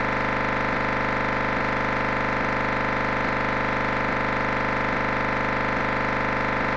NXDN96IDLE.mp3